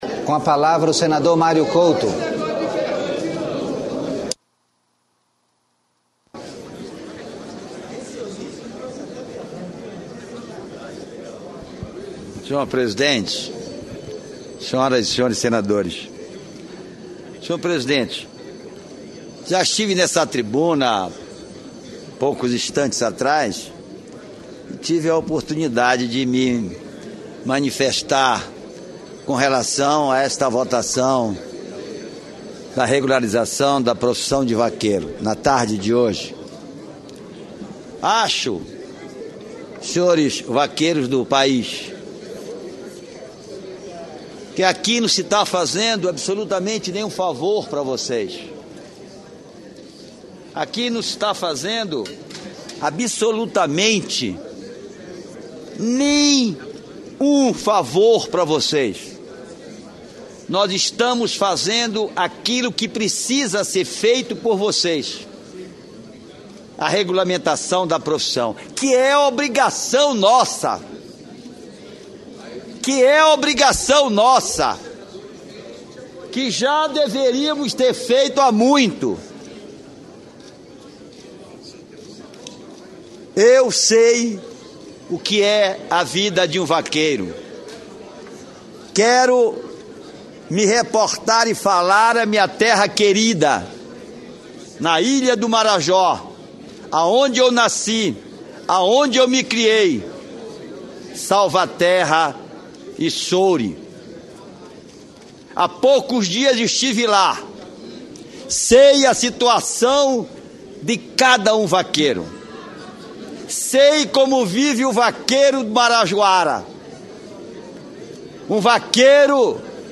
Sessão de aprovação da PEC da Música e do PL da profissão de vaqueiro (2)